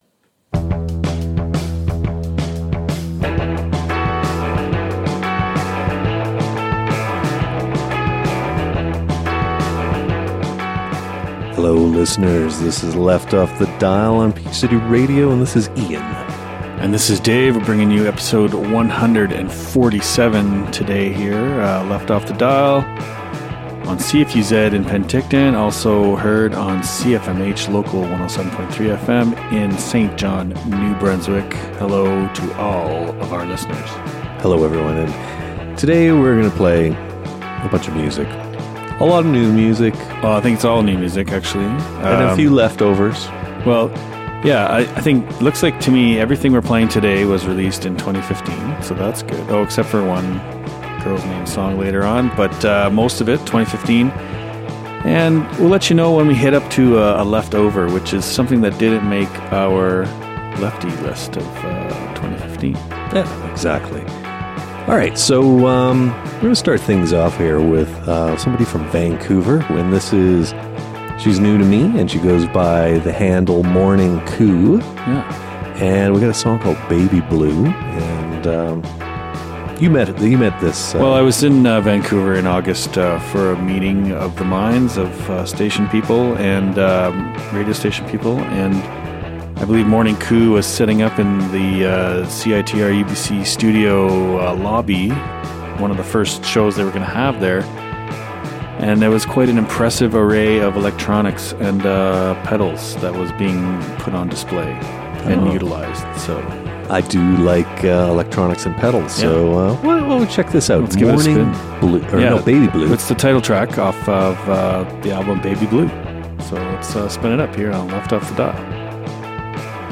Back at it for a new season with new indie jams and some left-over Leftys.